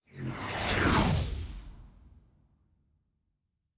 Arcane Spell 23.wav